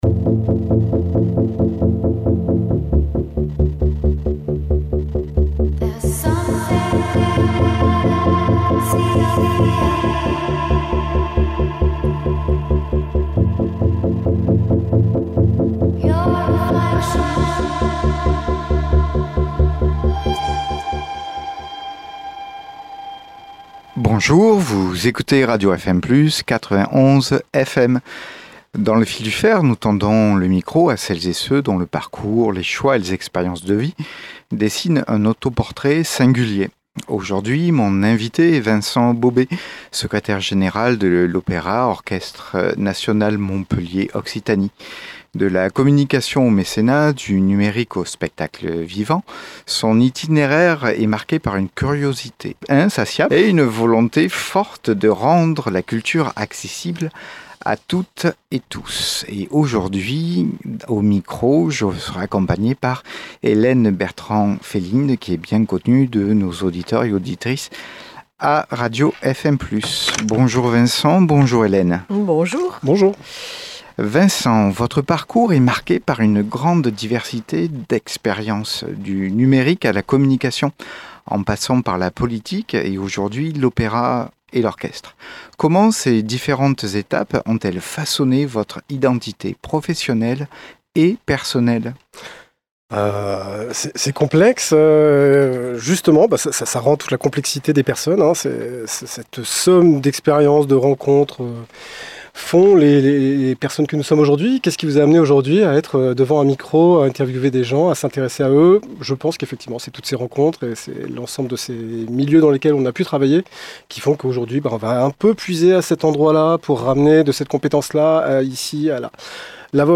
Dans Le Fil du Faire, nous tendons le micro à celles et ceux dont le parcours, les choix et les expériences de vie dessinent un autoportrait singulier.